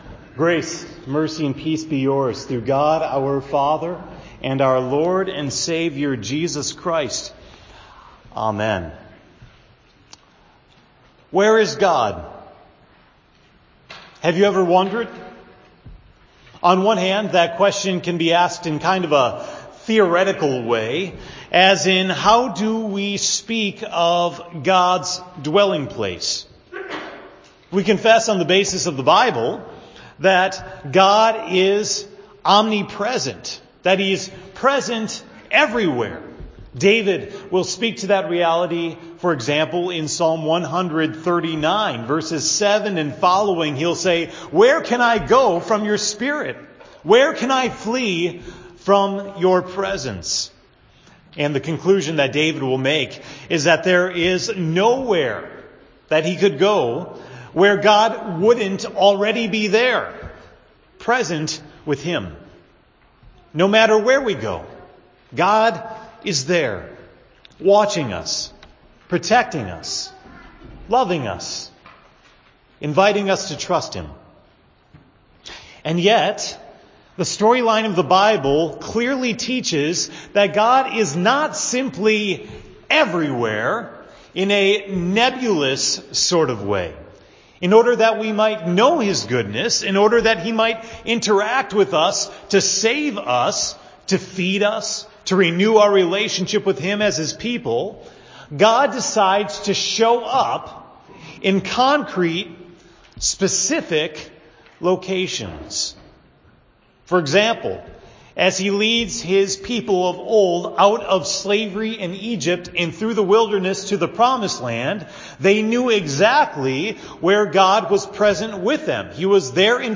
The sermon for March 4, 2018 at Hope Text: John 2:13-25